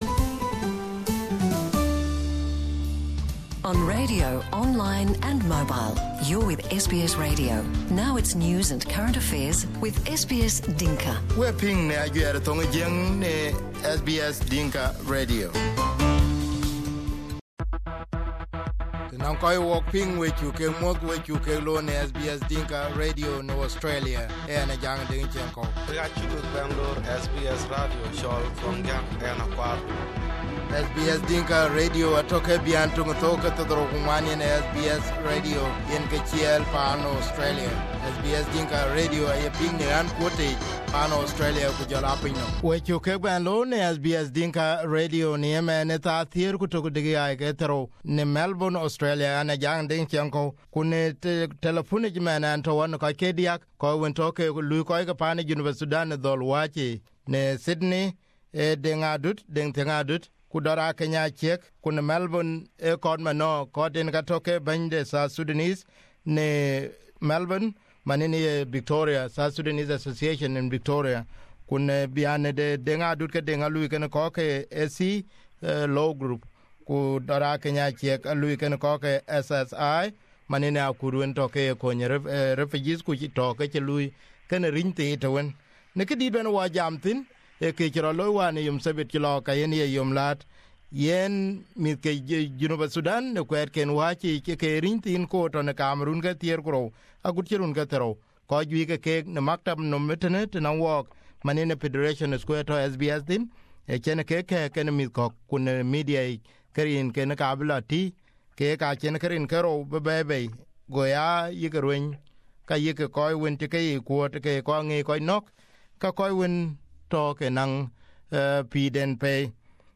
Two South Sudanese lawyers and a youth worker discuss issues facing young people in Australia.